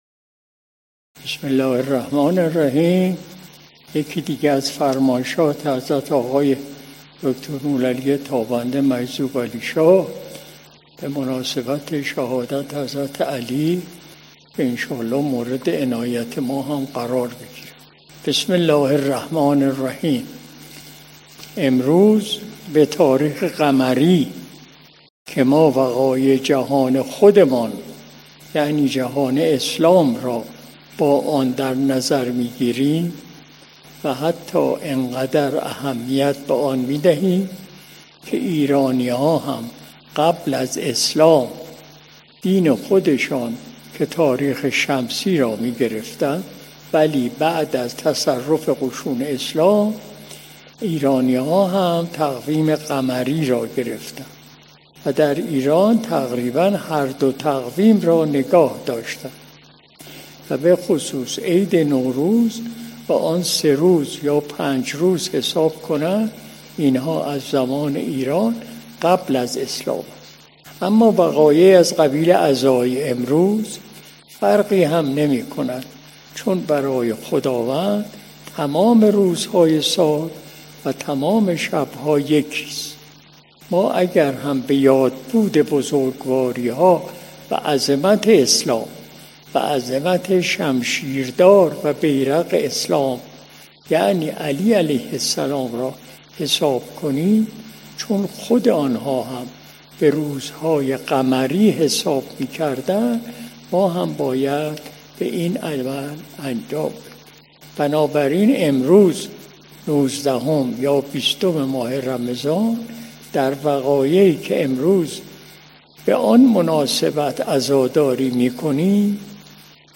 قرائت ‌متن فرمایش حضرت آقای حاج دکتر نورعلی تابنده «مجذوب‌علیشاه» طاب‌ثراه به مناسبت شهادت حضرت علی (ع)
مجلس شب دوشنبه ۲۰ فروردین ۱۴۰۲ شمسی – ۱۸ رمضان ۱۴۴۴ قمری